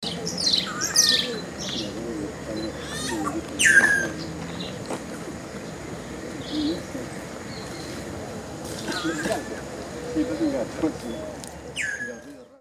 Boyero Negro (Cacicus solitarius)
Nombre en inglés: Solitary Cacique
Fase de la vida: Adulto
Localidad o área protegida: Reserva Ecológica Costanera Sur (RECS)
Condición: Silvestre
Certeza: Fotografiada, Vocalización Grabada